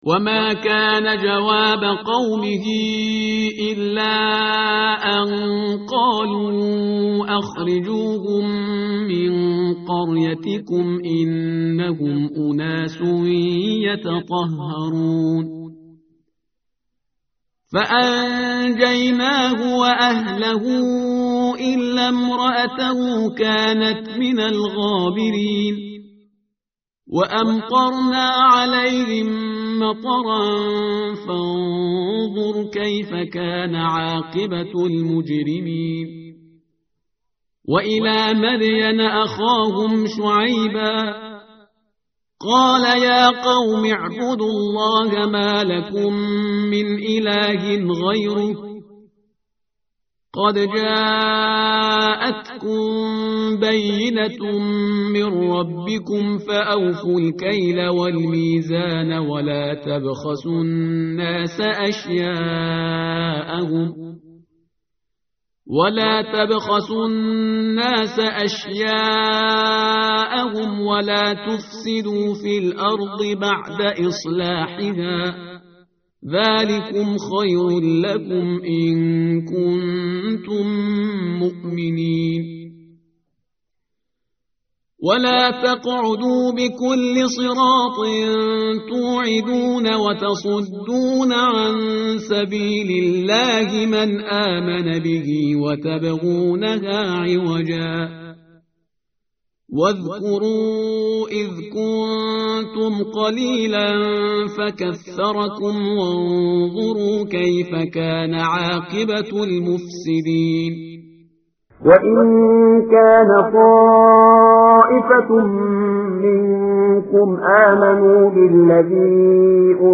tartil_parhizgar_page_161.mp3